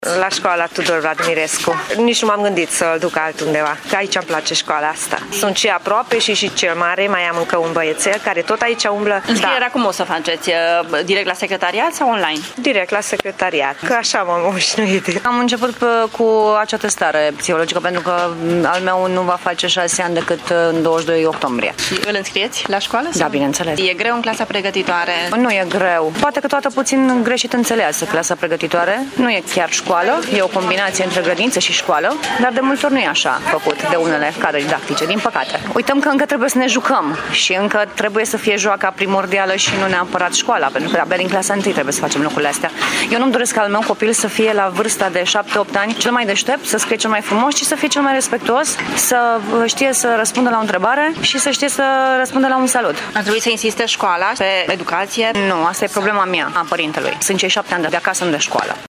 Părinții spun că sunt mulțumiți de organizarea înscrierilor la școală însă nu întotdeauna este bine înțeleasă clasa pregătitoare: